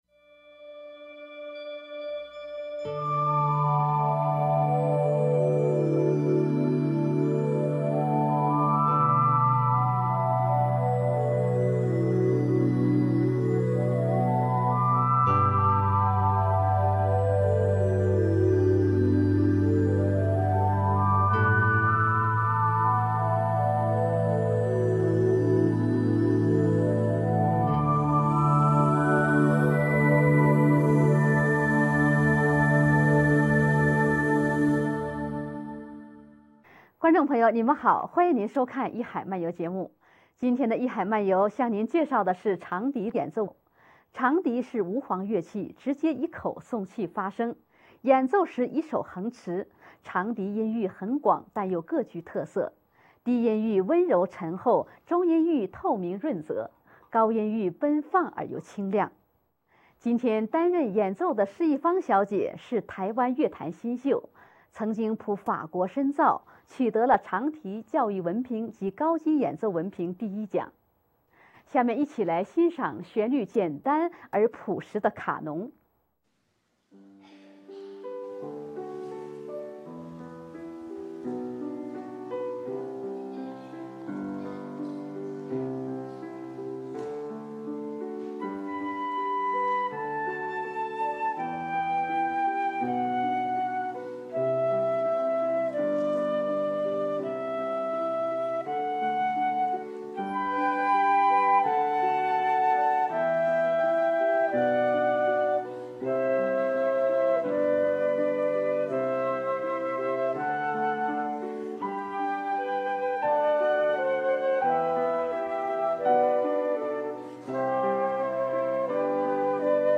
[情報] 藝海漫遊第20集:長笛獨奏 - 看板clmusic